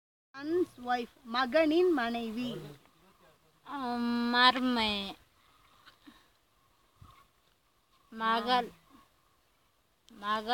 Elicitation of words about kinship terms - Part 22